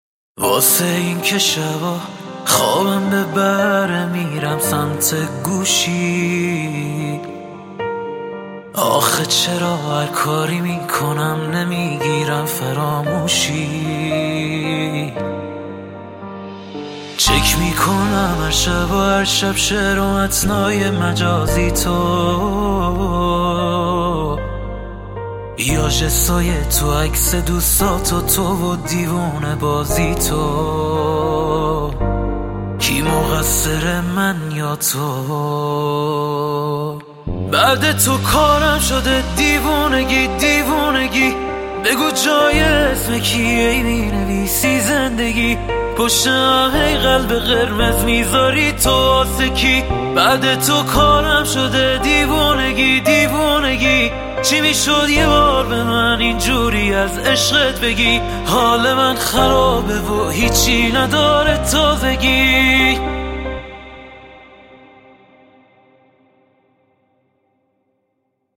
دانلود آهنگ غمگین